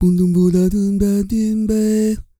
E-CROON 3001.wav